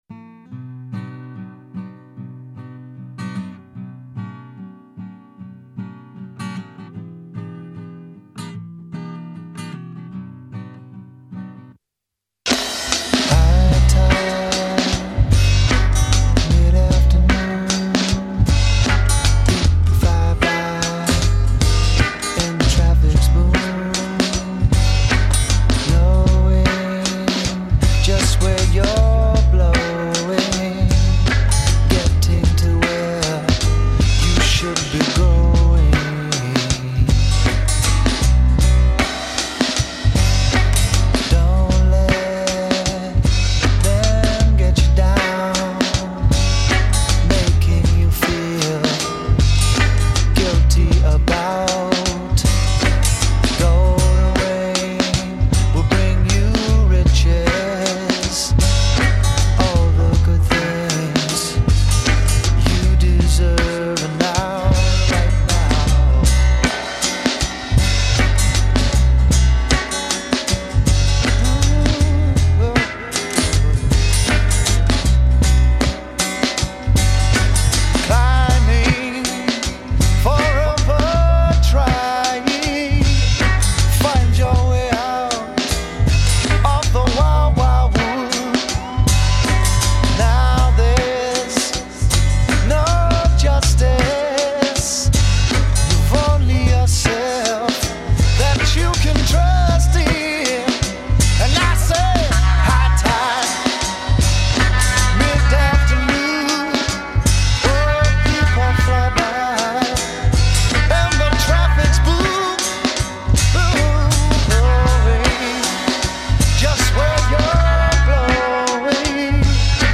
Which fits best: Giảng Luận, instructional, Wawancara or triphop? triphop